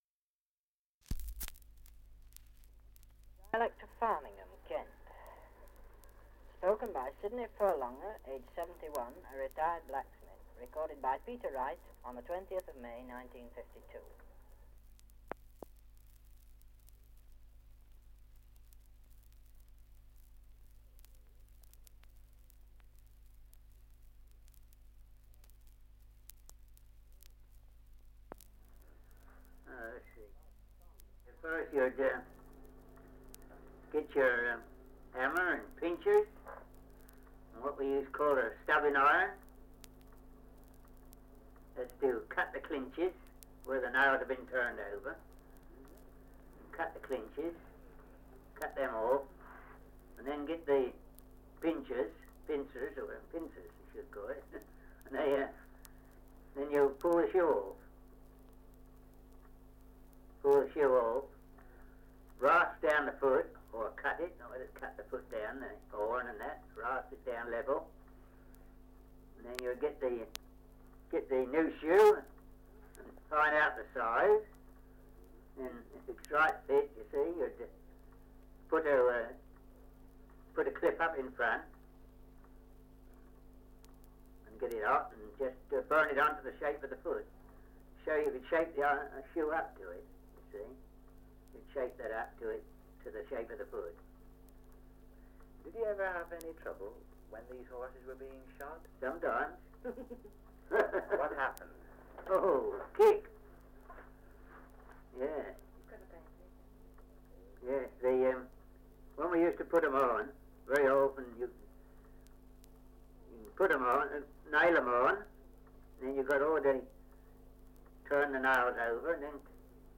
Survey of English Dialects recording in Farningham, Kent
78 r.p.m., cellulose nitrate on aluminium